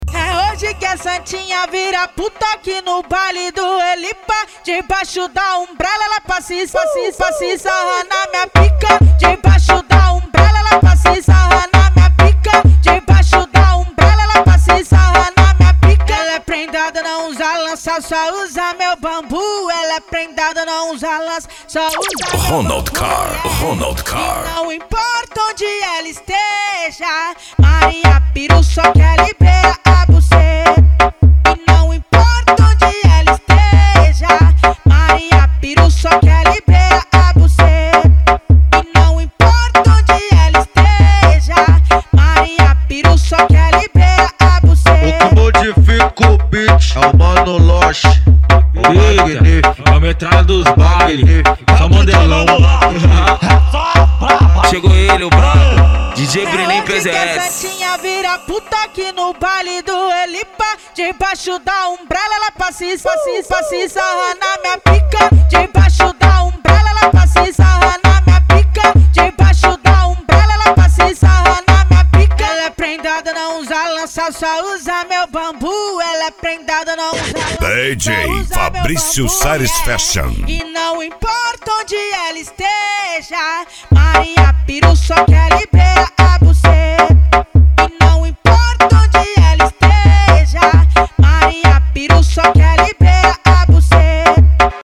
Funk
Musica Electronica